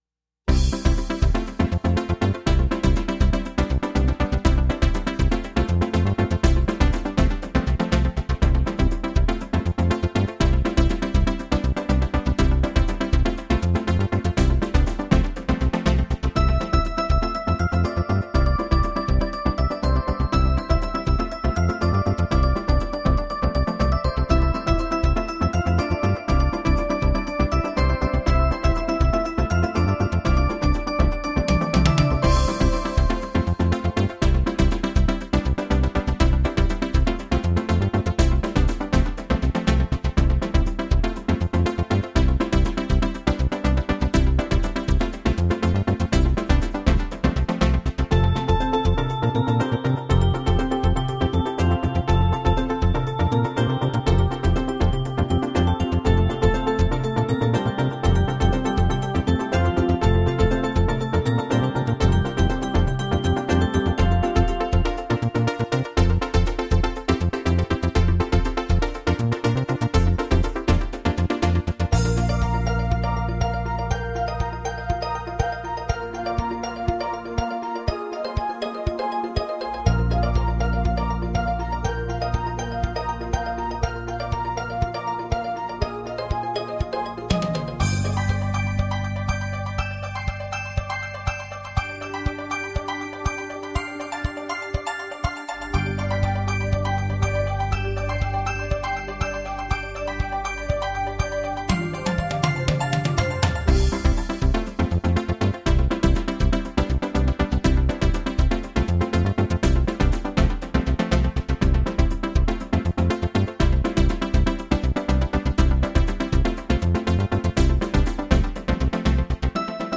Фоновая музыка для веселой сценки